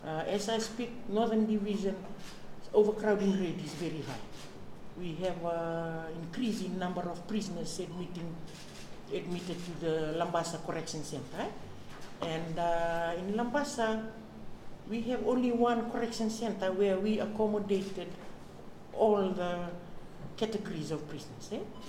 This has been highlighted by Fiji Corrections Service Acting Commissioner Salote Panapasa while speaking to members of the media ahead of the Employment Expo and Yellow Ribbon Walk scheduled for this weekend.